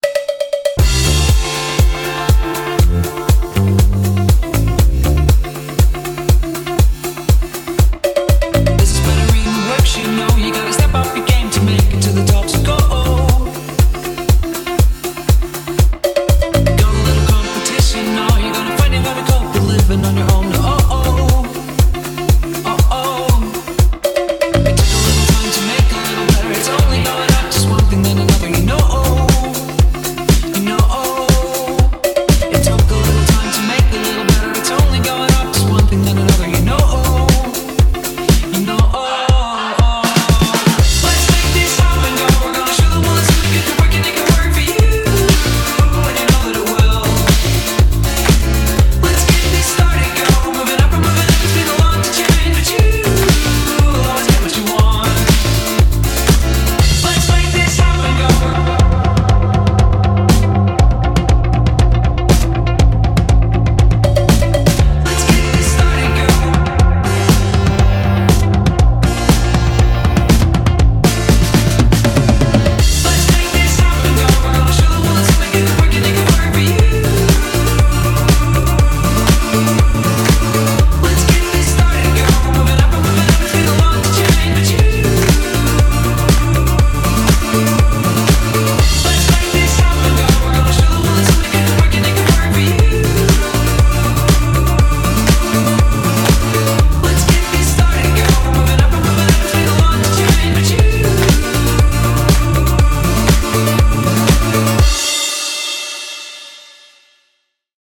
BPM120
Audio QualityPerfect (High Quality)
disco house-inspired